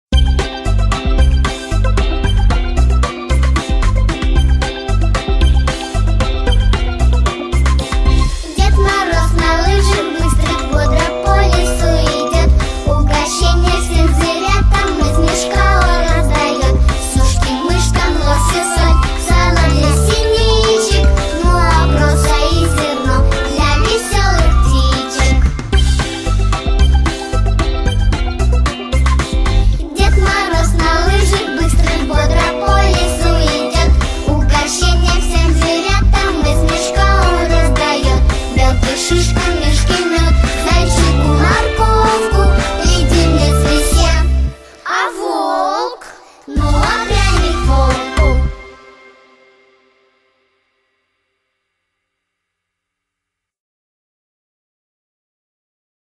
• Качество: Хорошее
• Жанр: Детские песни
Детская песня